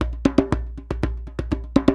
120 JEMBE4.wav